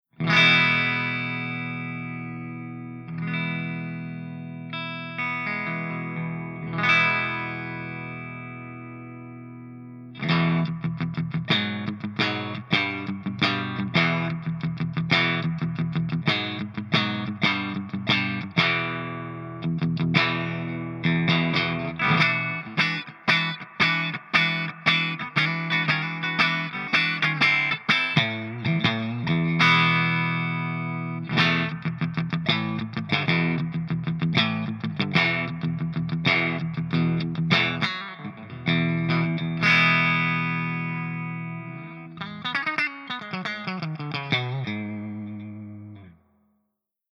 095_HIWATT_STANDARDCLEAN_GB_P90.mp3